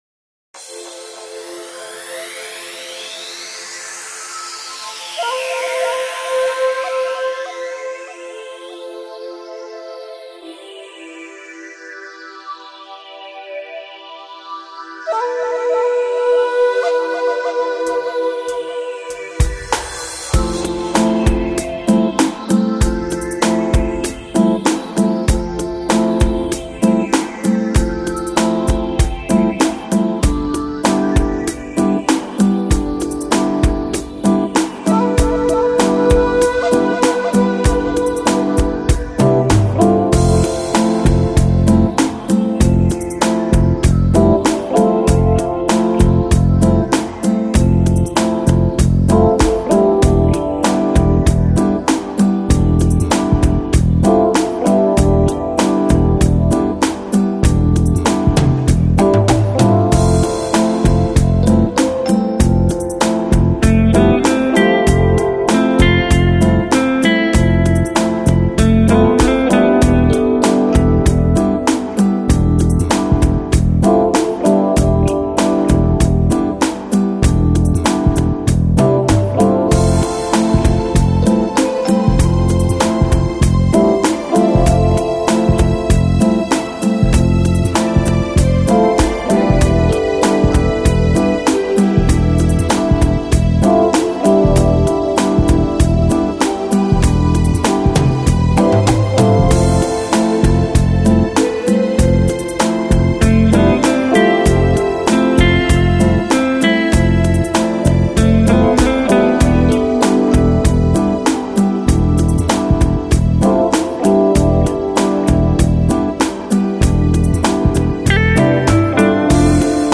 Downtempo, Lounge, Chill House